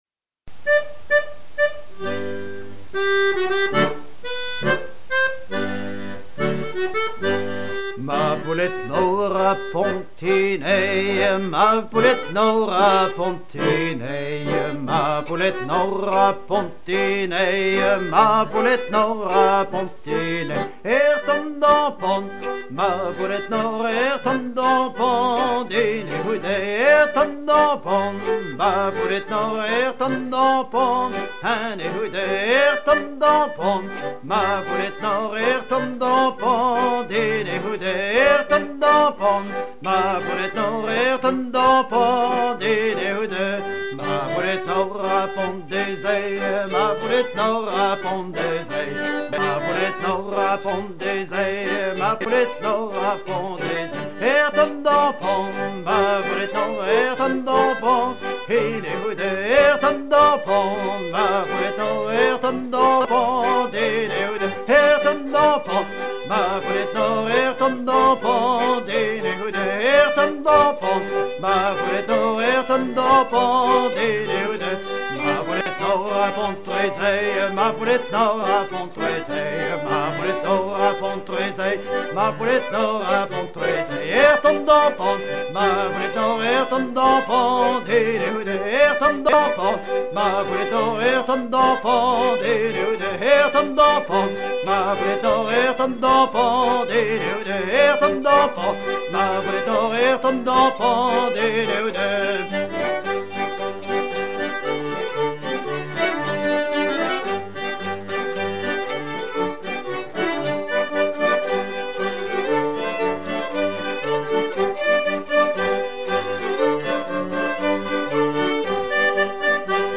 danse autour d'une poule hypnotisée par la flamme d'une bougie
Pièce musicale éditée